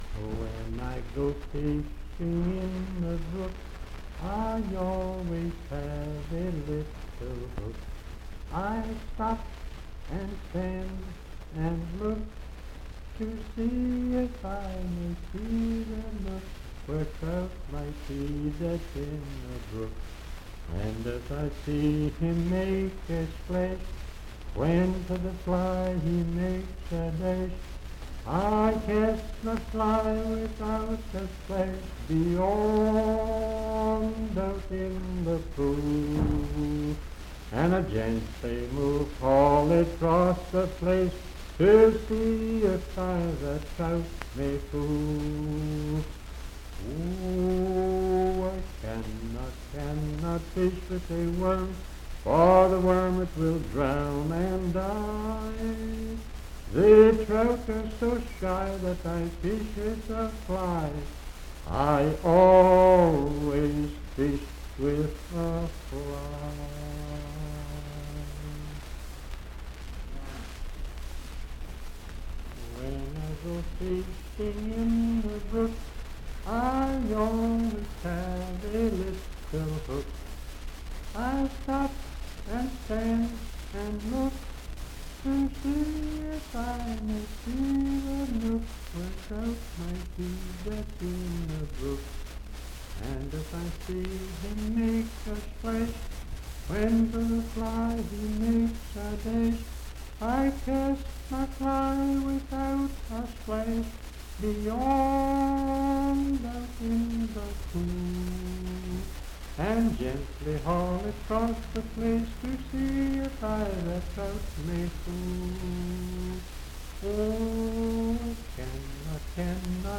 Unaccompanied vocal music
Verse-refrain 3(2-9).
Voice (sung)
Pocahontas County (W. Va.)